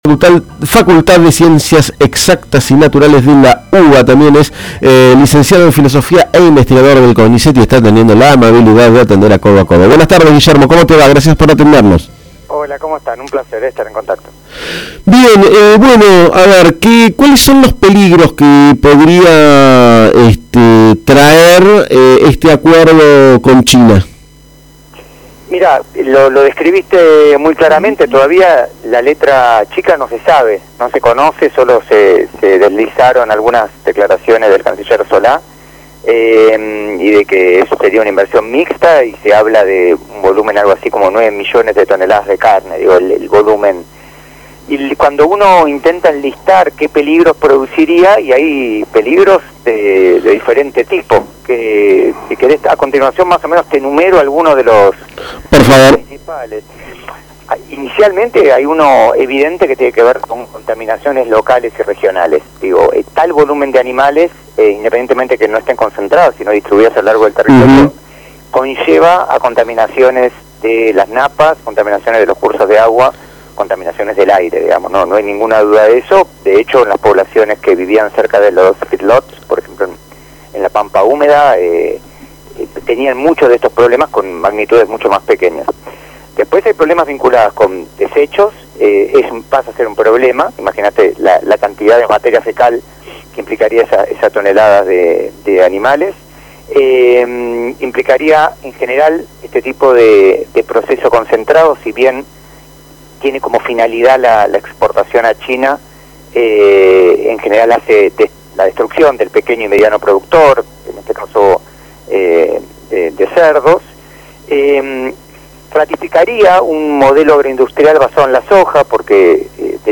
En Codo a Codo conversamos al respecto